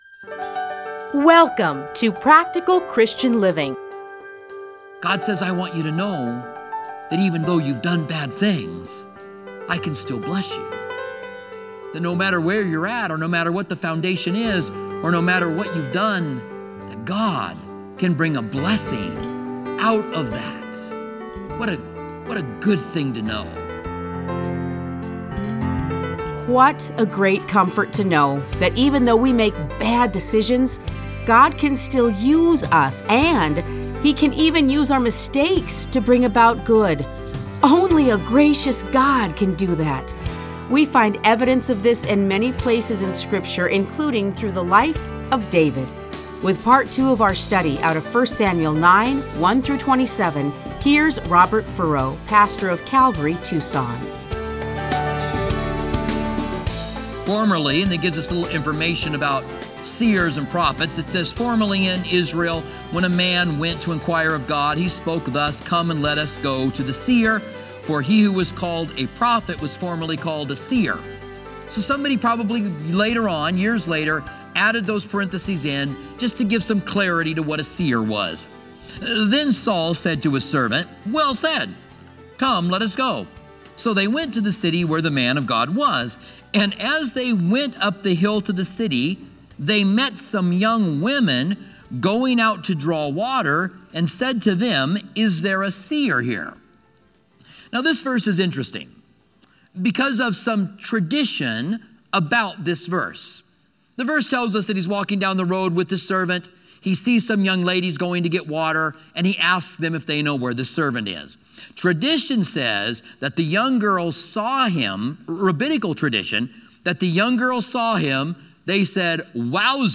Listen to a teaching from 1 Samuel 9:1-27.